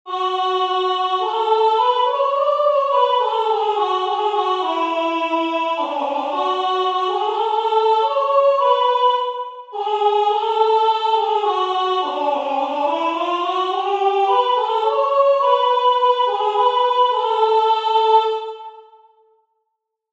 Só voz: